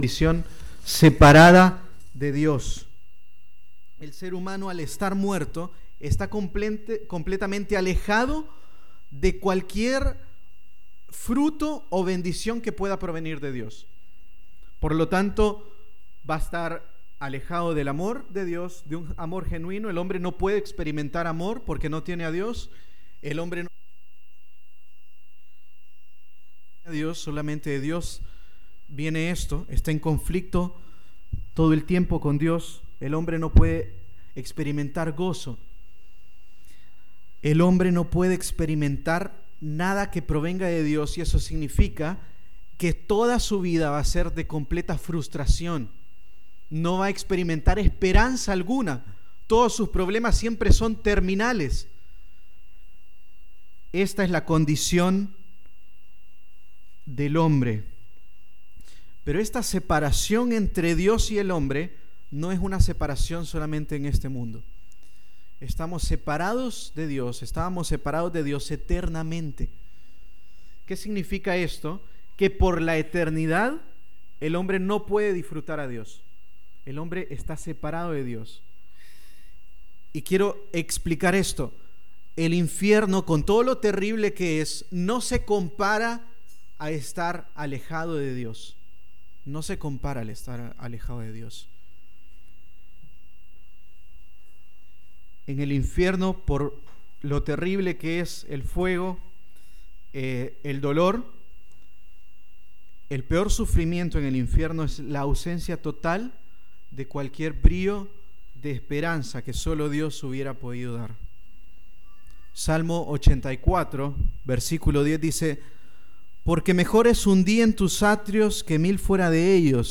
2017 Sermón Efesios 2:1 al 10